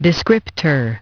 Transcription and pronunciation of the word "descriptor" in British and American variants.